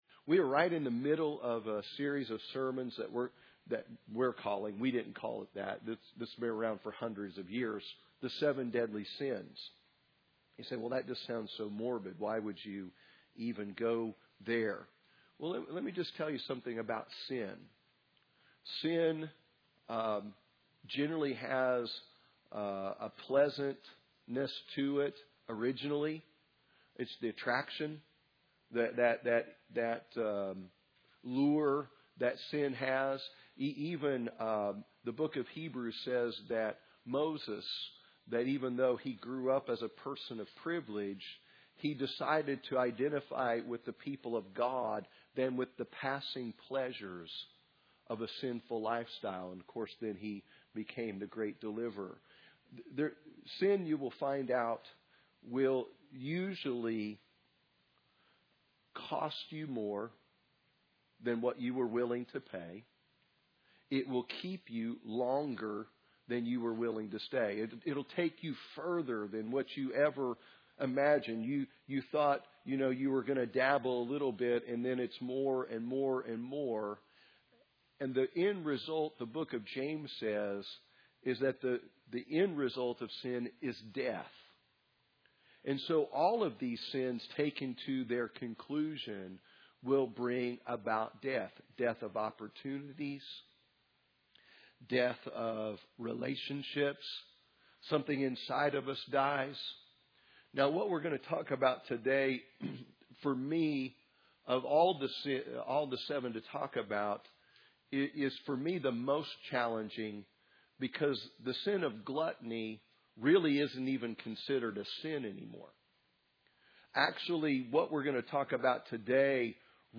Sunday Morning Service The Seven Deadly Sins